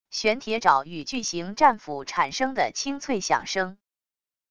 玄铁爪与巨型战斧产生的清脆响声wav音频